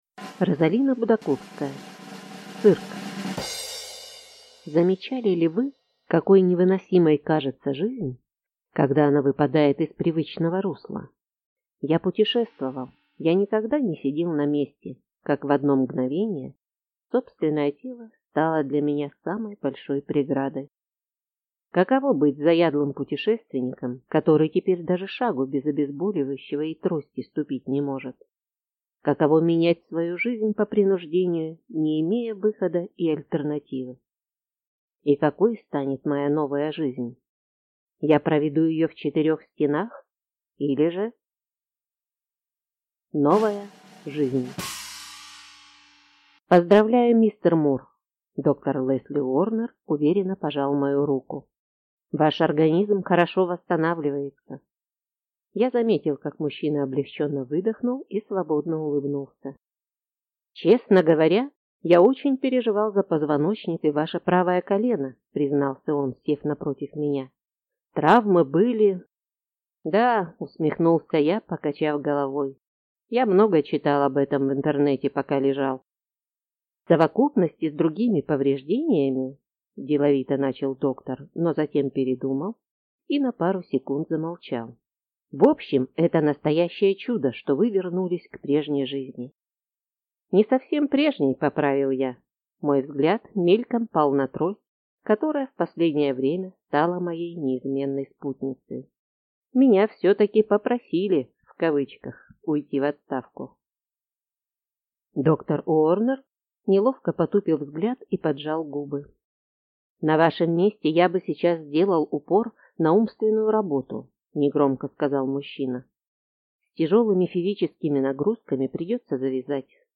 Аудиокнига Цирк | Библиотека аудиокниг